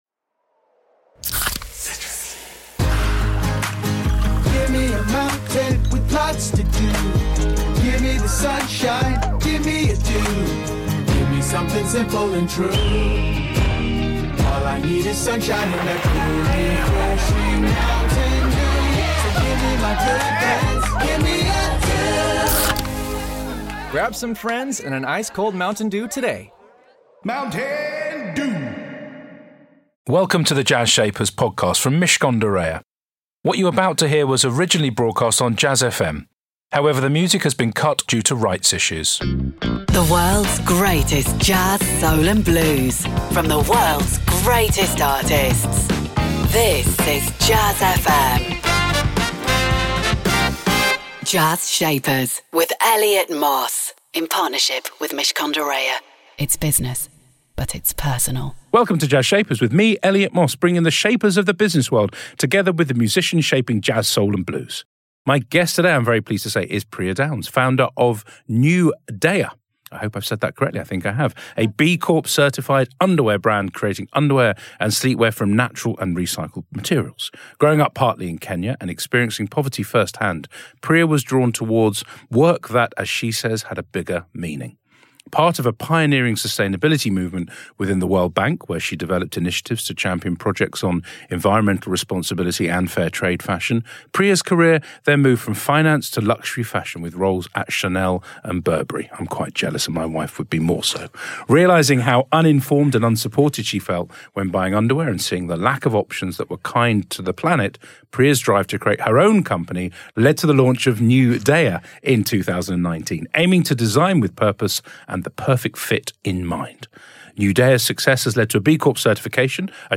Jazz Shapers features interviews with founders - business shapers who, like the shapers of jazz, soul and blues have defied convention, broken the mould and gone on to achieve great success.